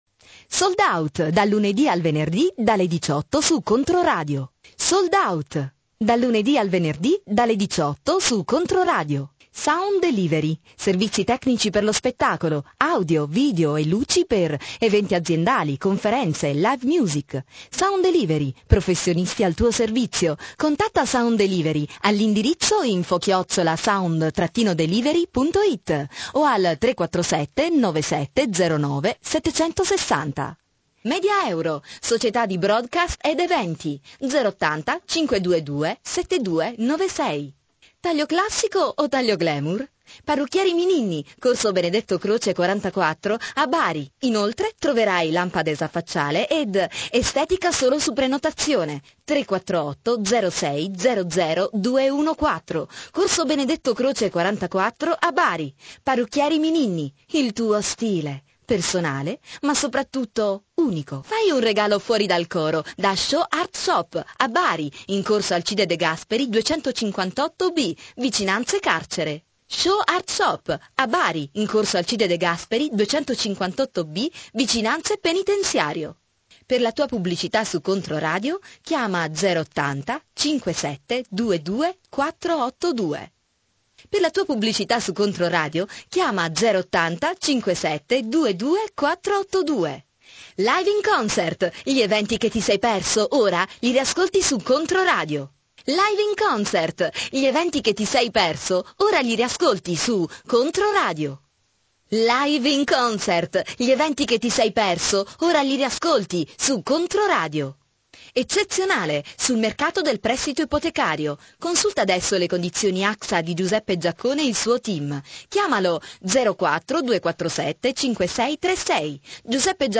Sprecherin italienisch. SPEAKER RADIOFONICA E PER CODINI PUBBLICITARI E REDAZIONALI,PRESENTATRICE.
Kein Dialekt